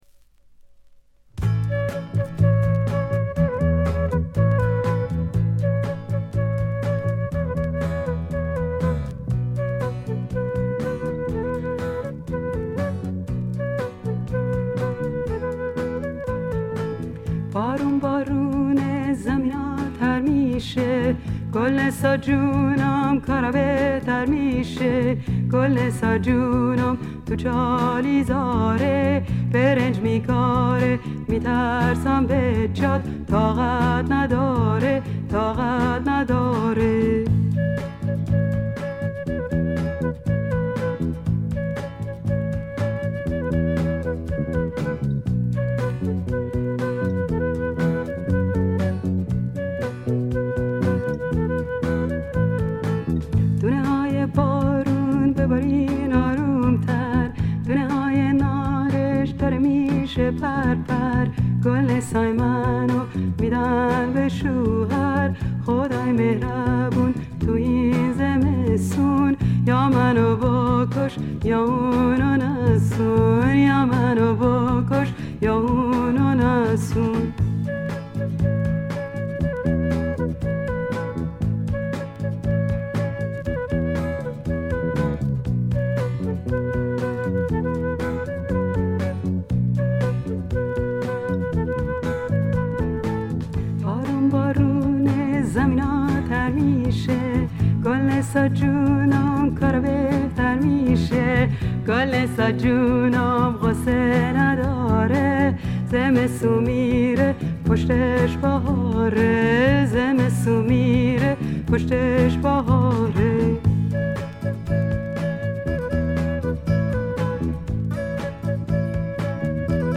静音部でのバックグラウンドノイズ、軽微なチリプチ。
ともあれ、どんな曲をやってもぞくぞくするようなアルトヴォイスがすべてを持って行ってしまいますね。
試聴曲は現品からの取り込み音源です。